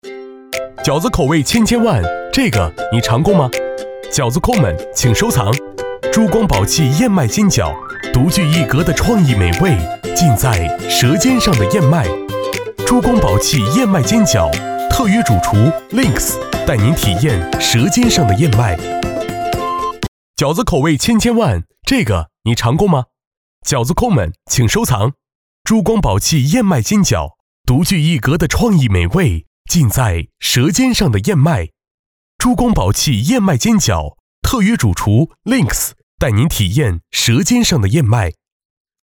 男50号配音师
普通话一级乙等
风格可以大气也可以年轻活力也可以emo